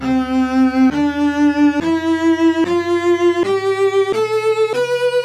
cello.wav